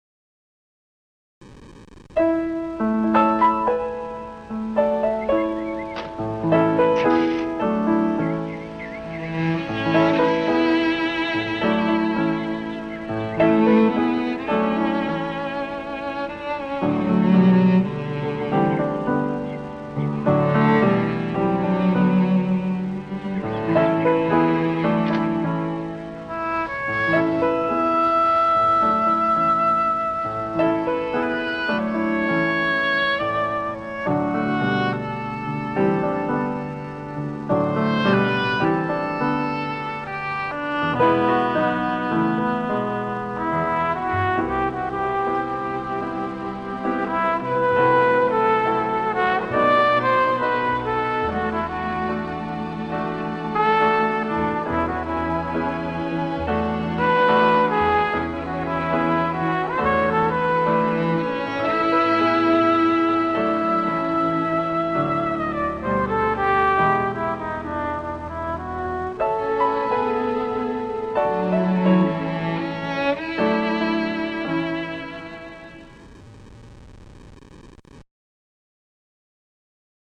Audio (slow; 1:20)